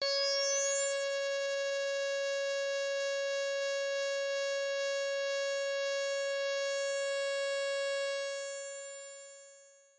描述：通过Modular Sample从模拟合成器采样的单音。
标签： CSharp6 MIDI音符-85 赤-AX80 合成器 单票据 多重采样
声道立体声